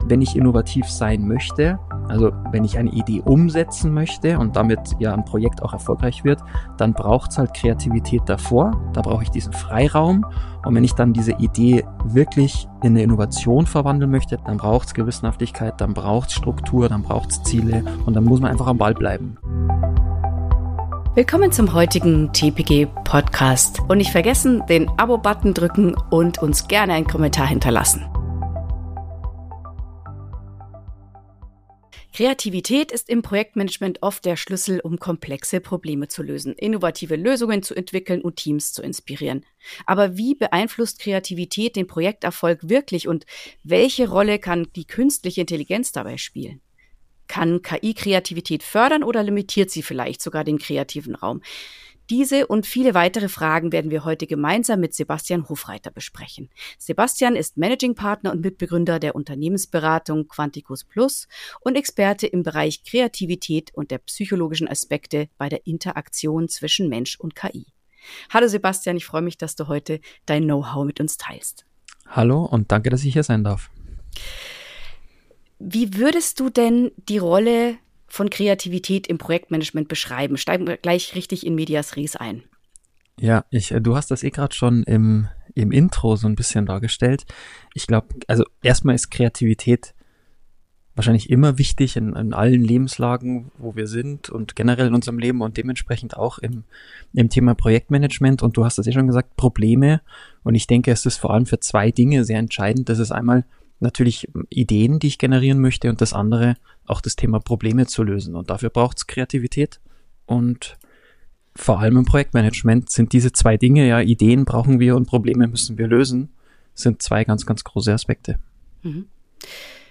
Lernen Sie von Interviews mit erfahrenen Personen aus der PM-Praxis.